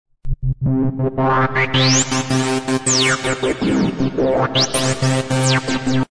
ちょっとしたゲートのような感じですね。
♪『Points』をゲート的に使った音♪(mp3)